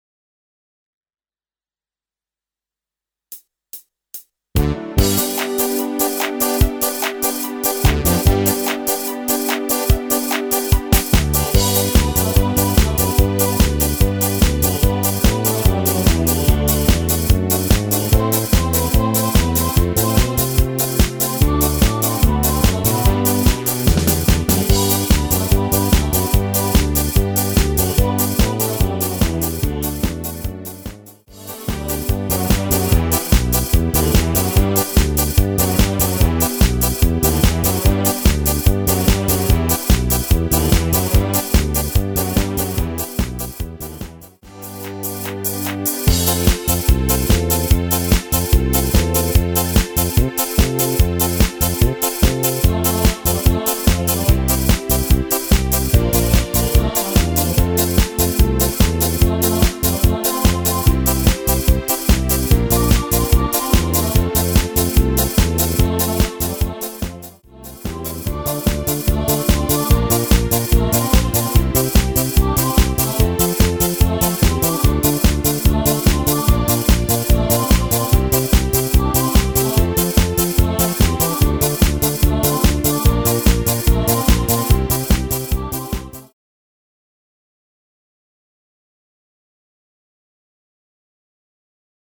Rubrika: Pop, rock, beat
- směs
HUDEBNÍ PODKLADY V AUDIO A VIDEO SOUBORECH